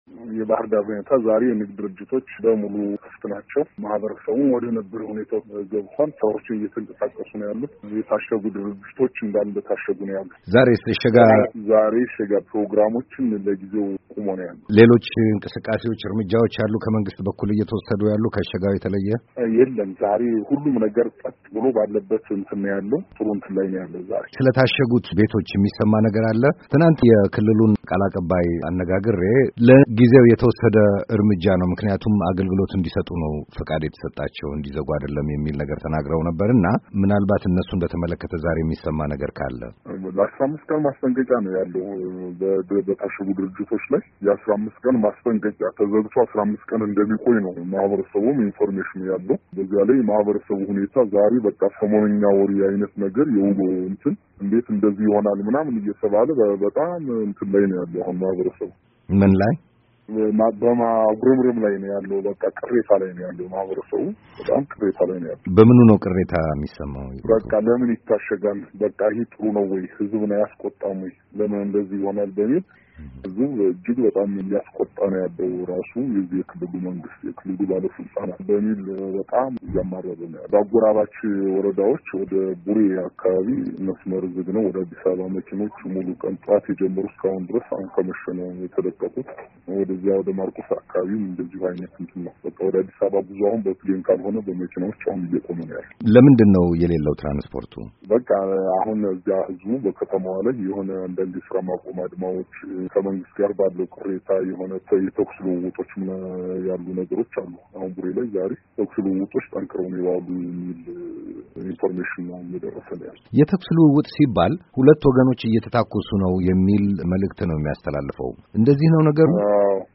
በባሕር ዳርና በአካባቢዋ የሐሙስና የዓርብ ሁኔታ ላይ ከአንድ የባሕር ዳር ነዋሪ የተደረገ ቃለ-ምልልስ